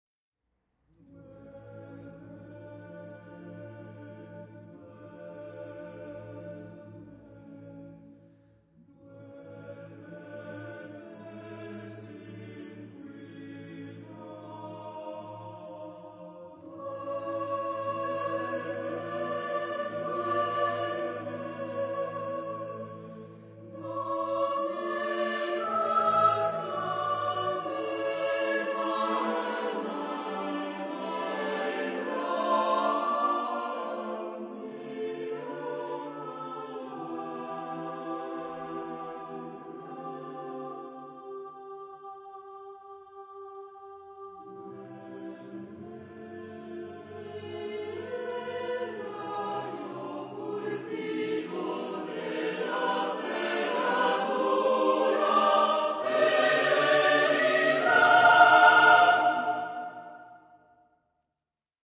SATBB (5 voices mixed) ; Full score.
Lullaby. Choir.
Tonality: A major